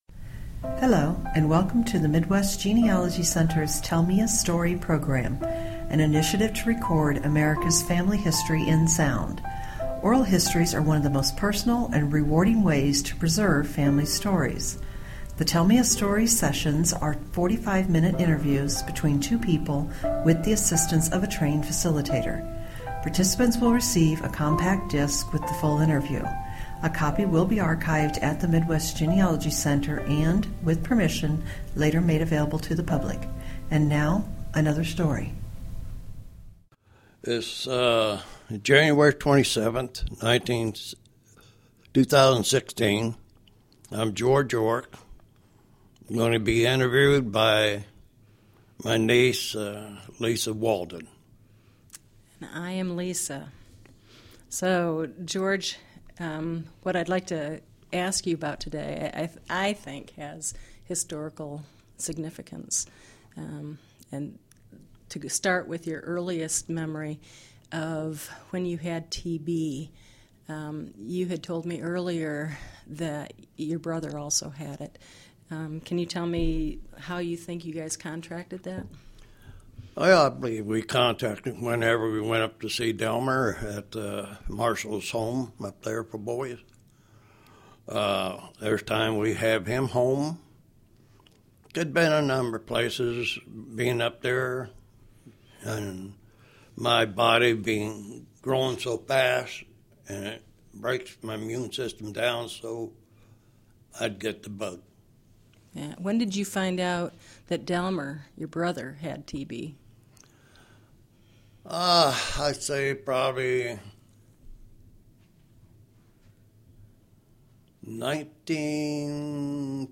Genealogy Family history Oral history